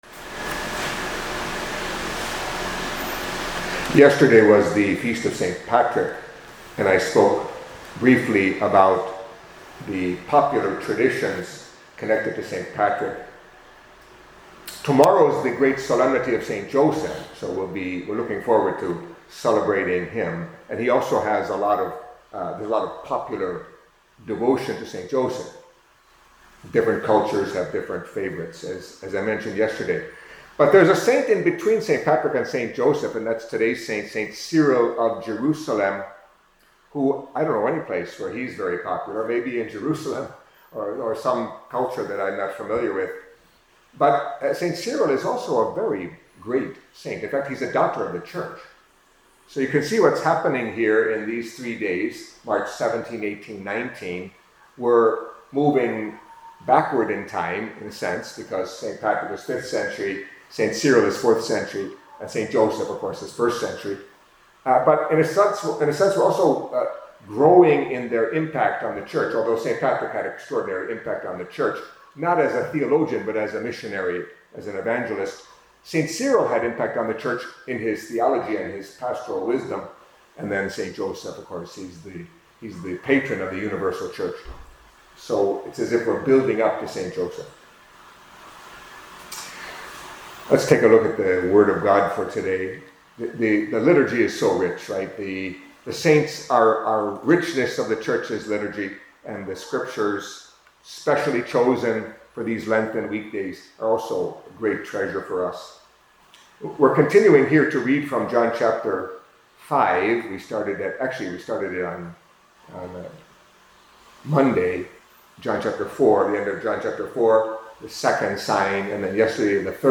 Catholic Mass homily for Wednesday of the Fourth Week of Lent